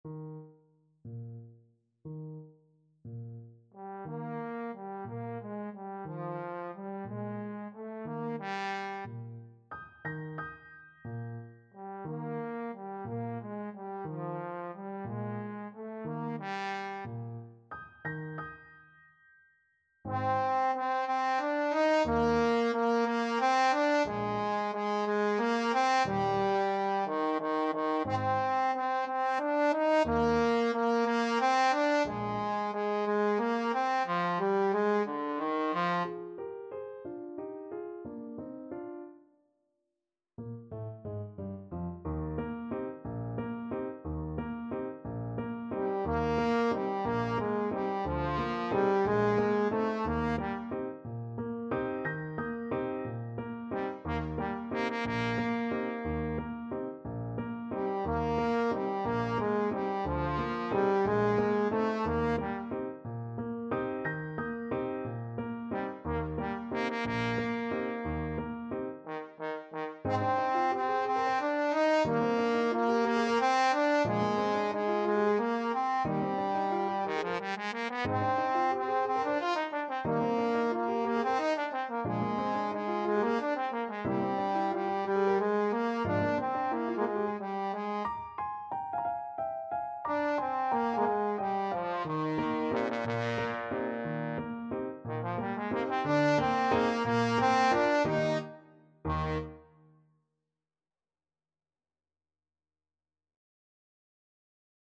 Classical Tchaikovsky, Pyotr Ilyich Theme from Capriccio Italien Trombone version
Trombone
Eb major (Sounding Pitch) (View more Eb major Music for Trombone )
6/8 (View more 6/8 Music)
Pochissimo pi mosso = 144 . =60
Classical (View more Classical Trombone Music)